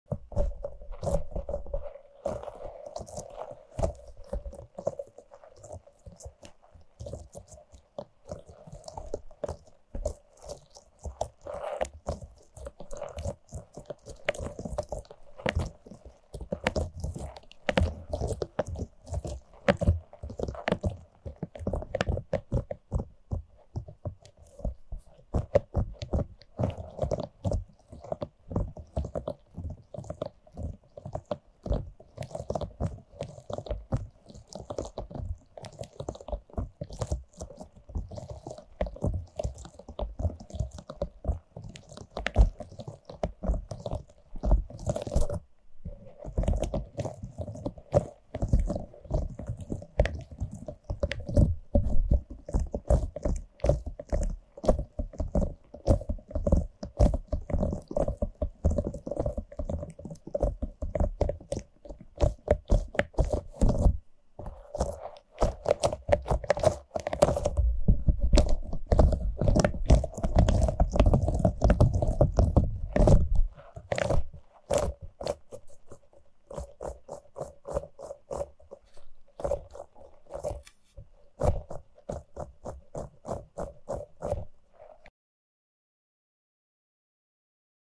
Stressball Tingles